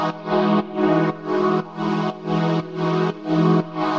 Index of /musicradar/sidechained-samples/120bpm
GnS_Pad-alesis1:4_120-E.wav